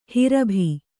♪ hirabhi